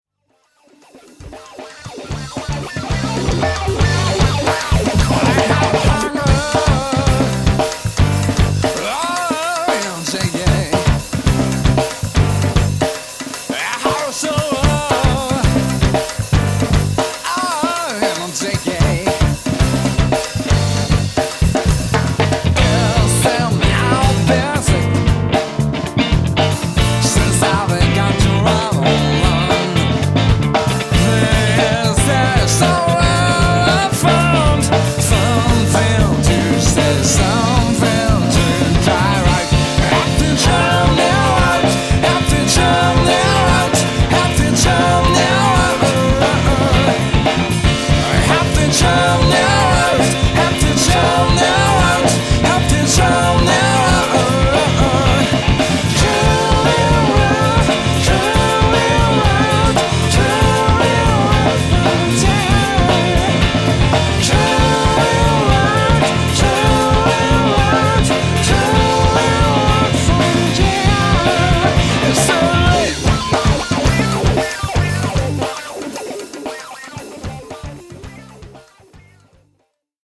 とてもソウルフルな演奏を聴かせてくれます。ボーカルがいい。